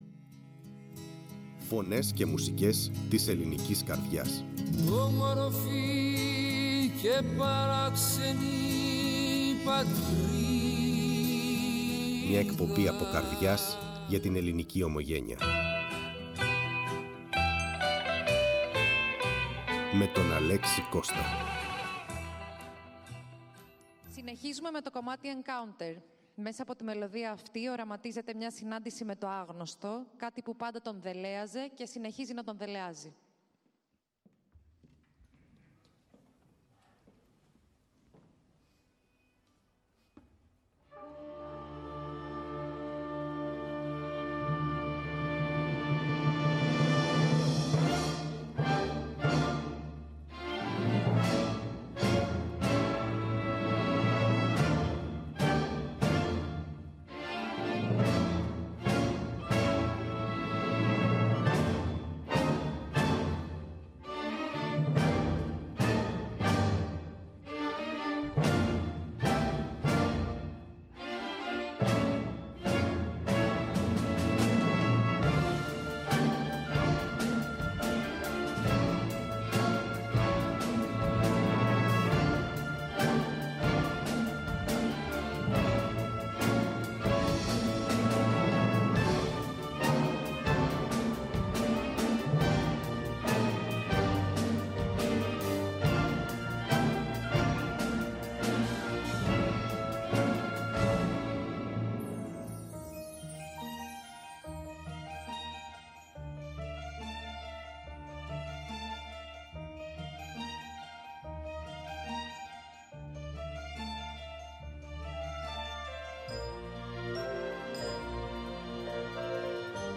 σε μια συζήτηση ουσίας, όπου η θάλασσα συνάντησε τη μουσική και το επιχειρείν τη δημιουργία.